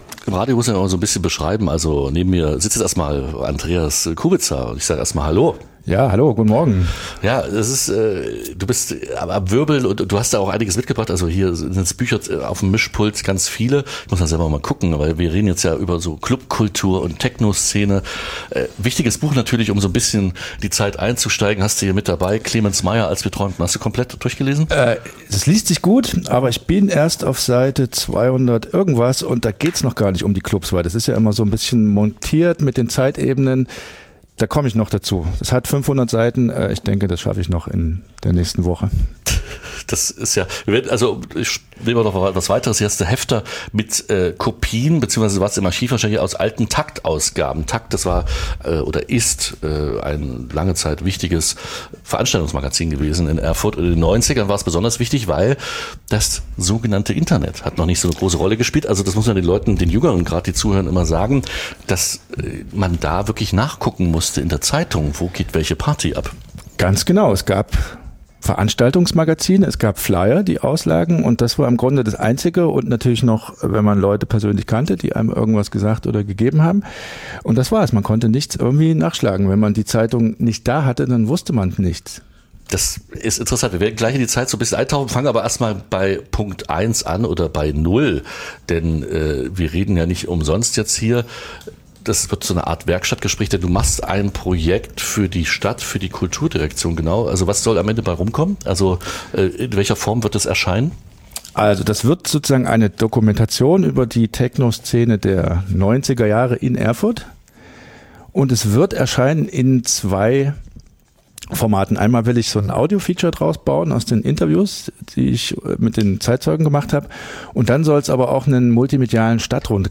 Zurück in die 90er - Ein Gespräch über Club-Kultur